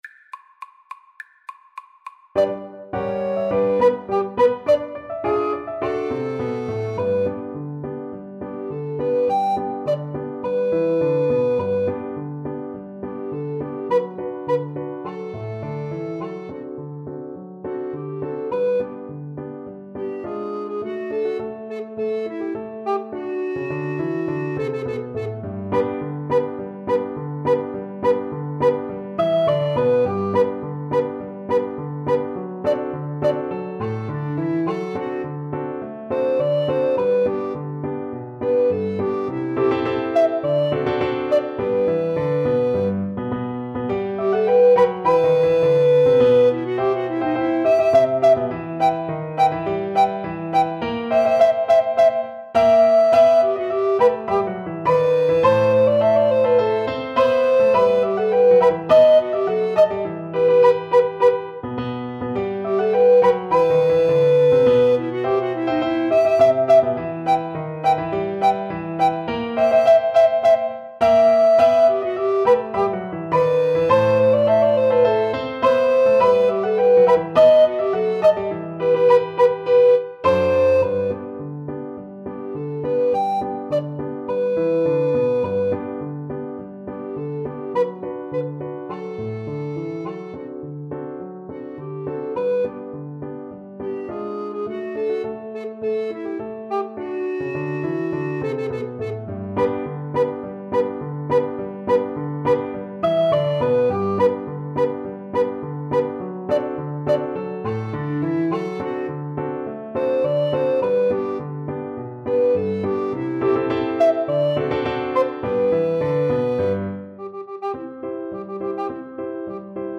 Alto RecorderTenor RecorderPiano
2/2 (View more 2/2 Music)
Quick March = c.104
Recorder Duet  (View more Intermediate Recorder Duet Music)
Classical (View more Classical Recorder Duet Music)